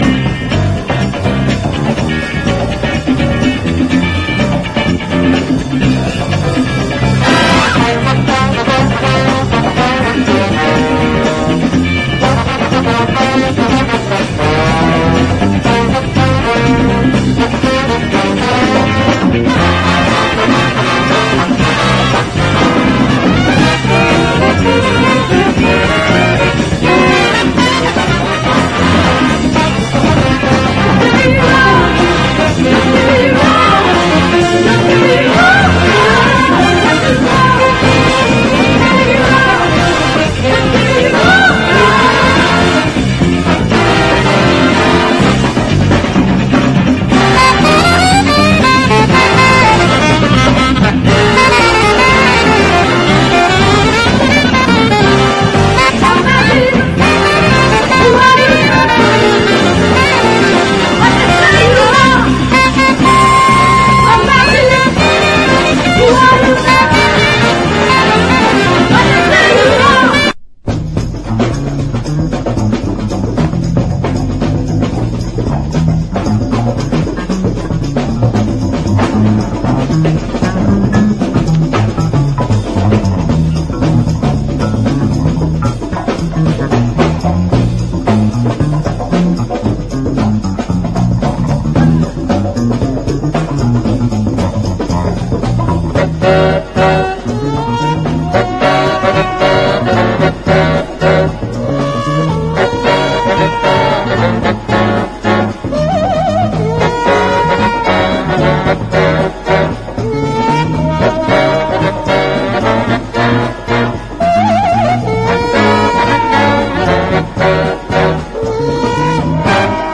JAZZ / DANCEFLOOR / ACID JAZZ (UK)